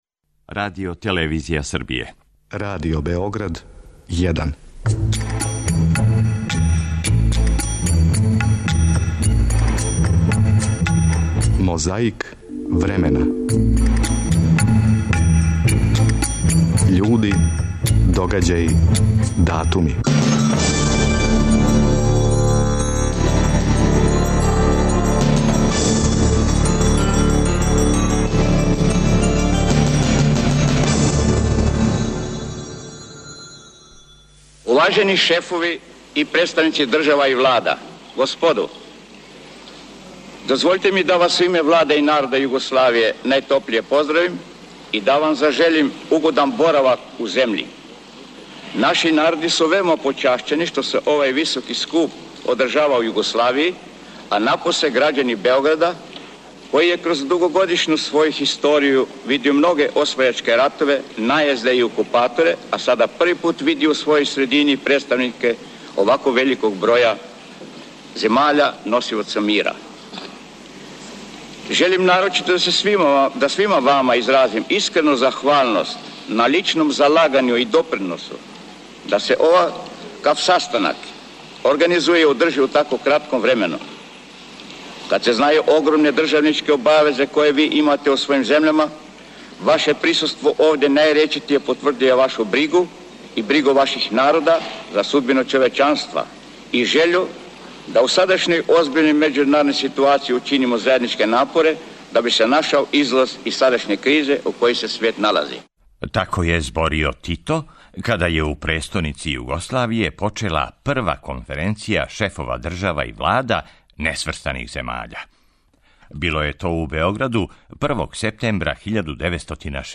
У прошлом веку, првог септембра 1961 године, у Београду почела је прва конференција шефова држава и влада несврстаних земаља. Звучна коцкица нас подсећа како је тада говорио Тито...
Подсећа на прошлост (културну, историјску, политичку, спортску и сваку другу) уз помоћ материјала из Тонског архива, Документације и библиотеке Радио Београда.